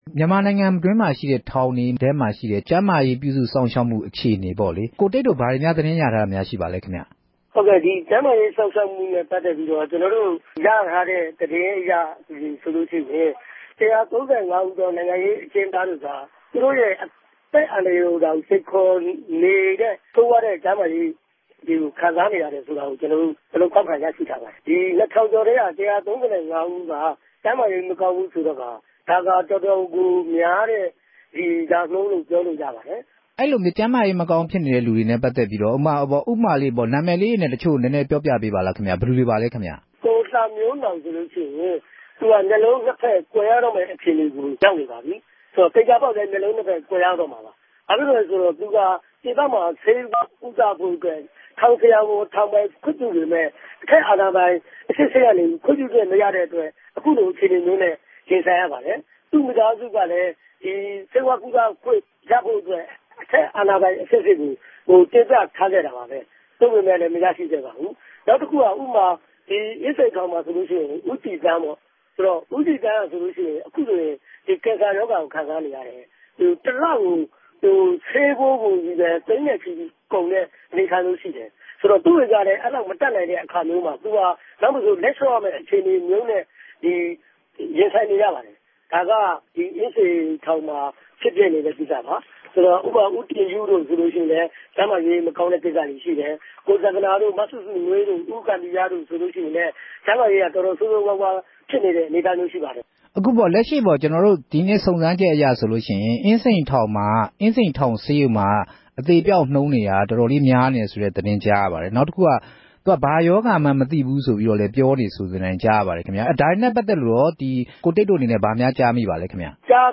ဆက်သြယ်မေးူမန်းခဵက်။